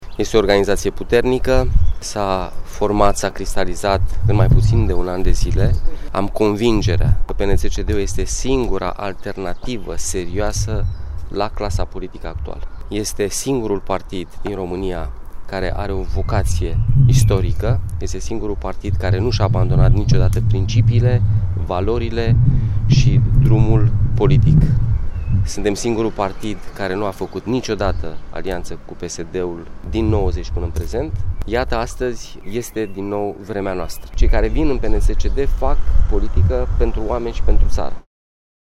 Lansarea oficială a candidaţilor PNȚCD a avut loc la Casa de Cultură a Sindicatelor din Reşiţa.